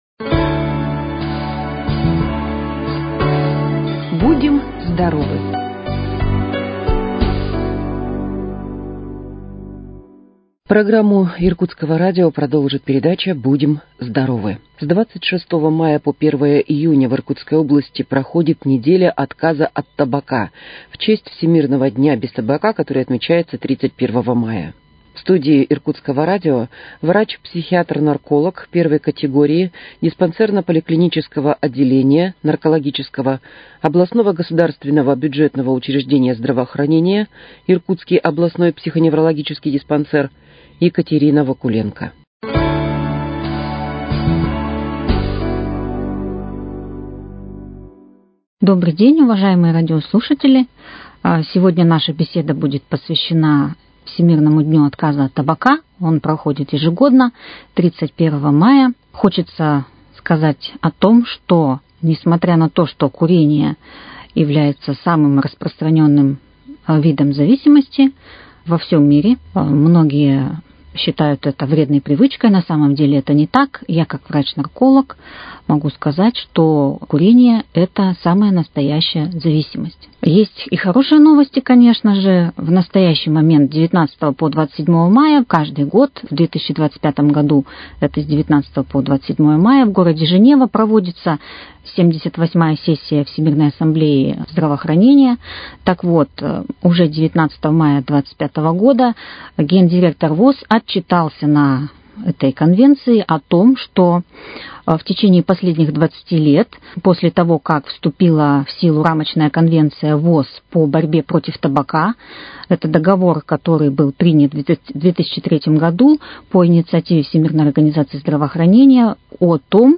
В студии Иркутского радио